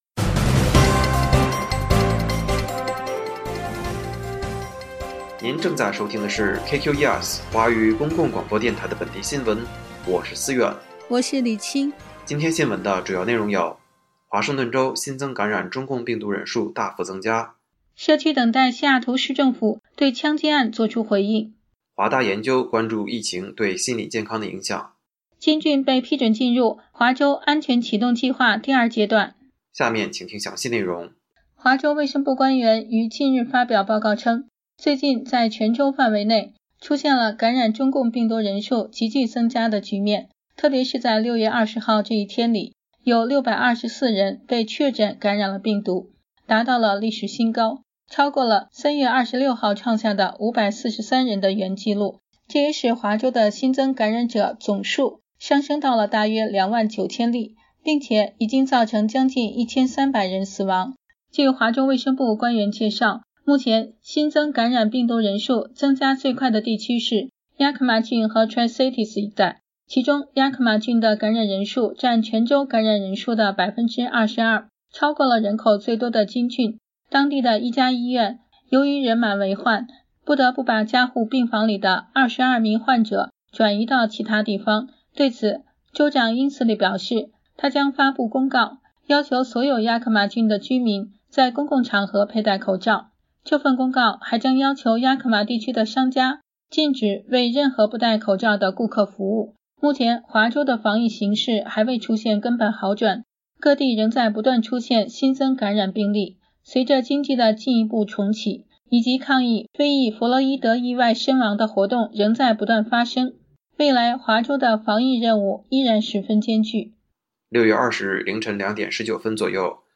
新聞廣播
每日新聞